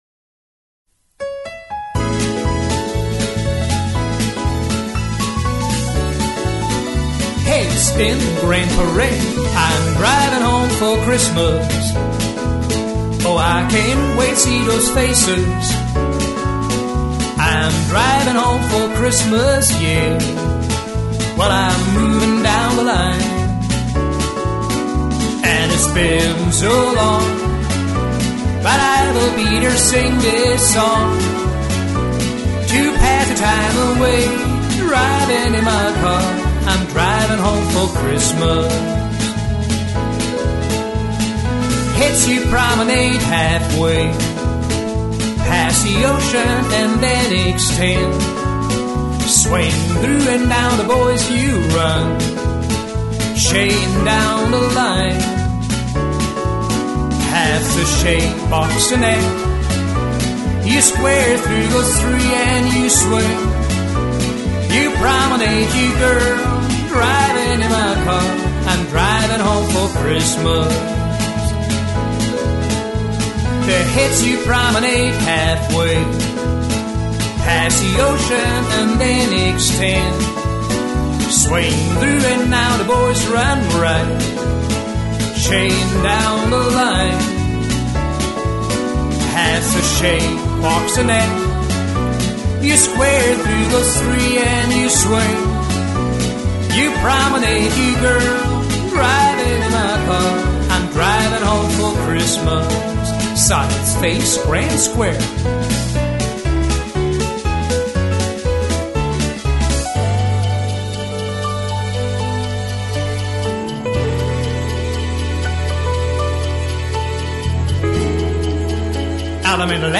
Vocal Tracks